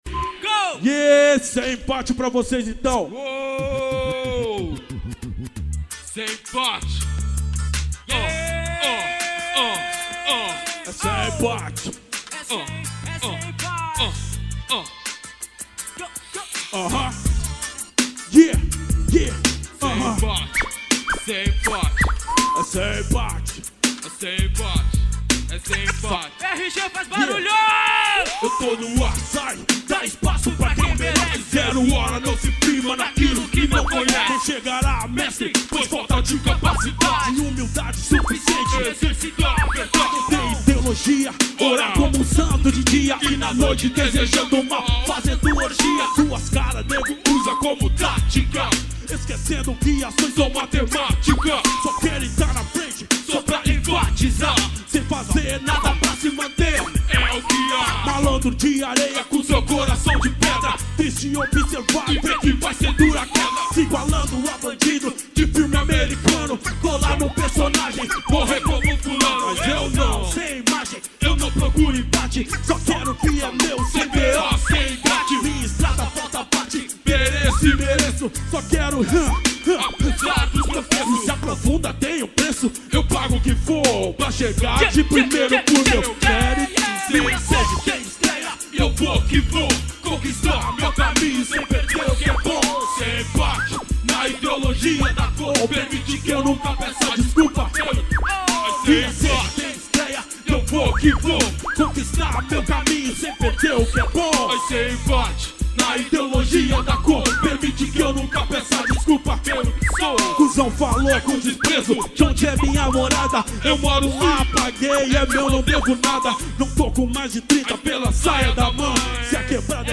ao vivo show mv bil 2011.